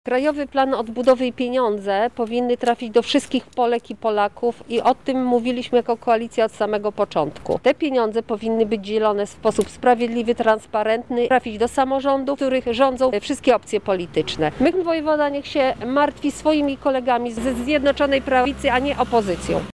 • wskazuje parlamentarzystka.